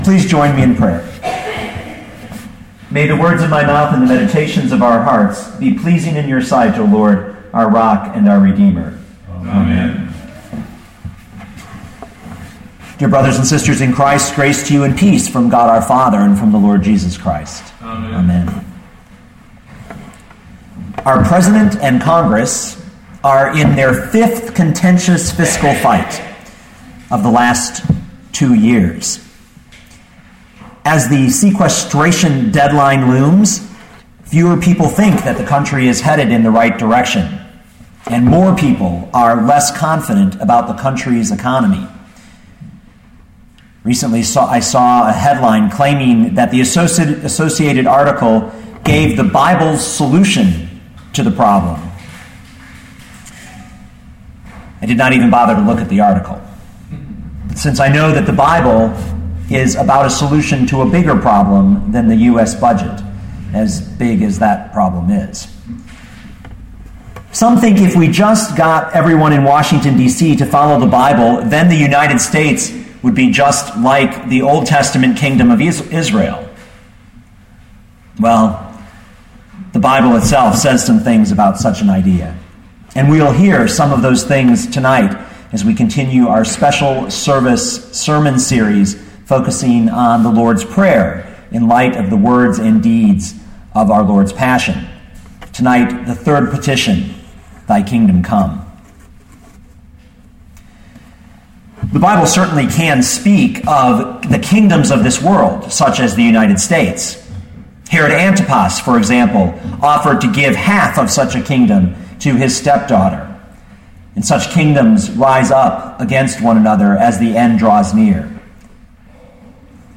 Luke 11:2 Listen to the sermon with the player below, or, download the audio.